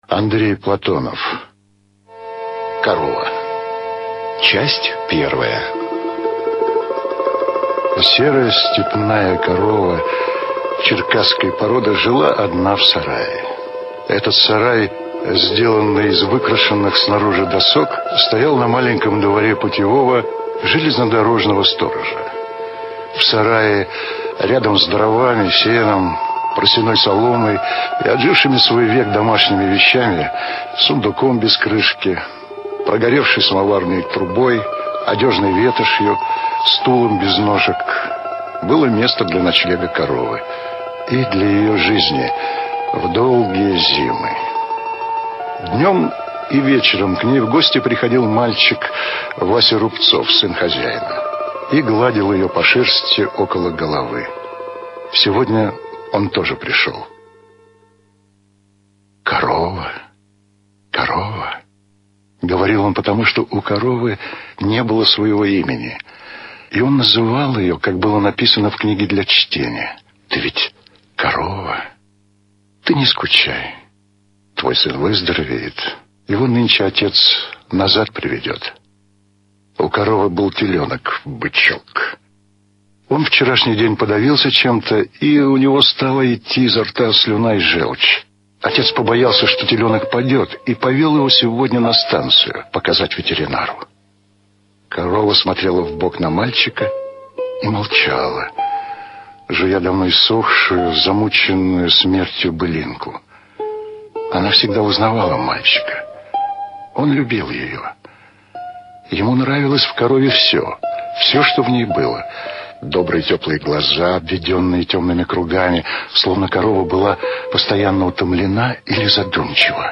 Корова - аудио рассказ Платонова А.П. Рассказ про смышлёного и работящего мальчика Васю Рубцова, сына путевого сторожа.